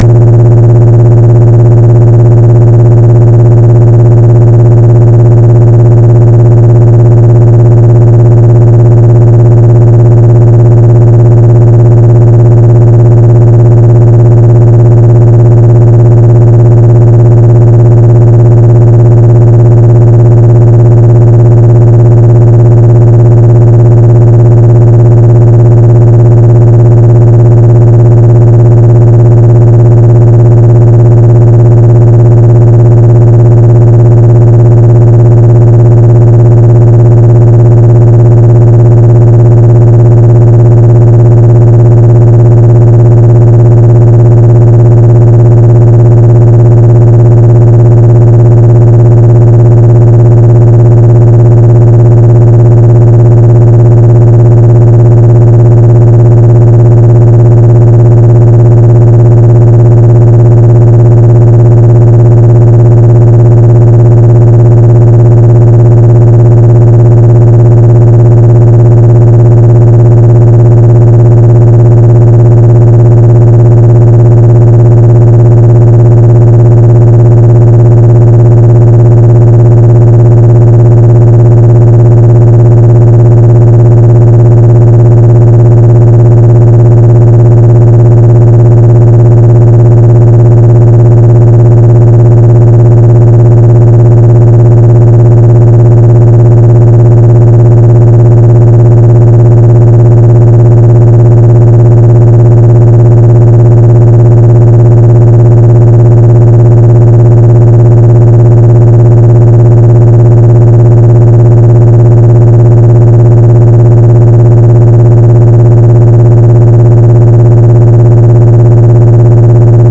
Som de proteção de privacidade